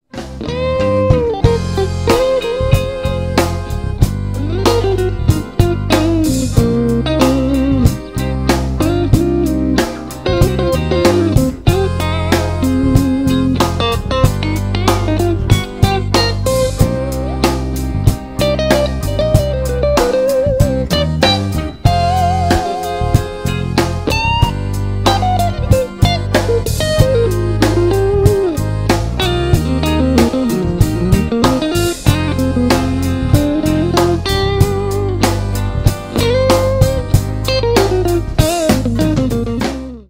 блюз
инструментал
спокойные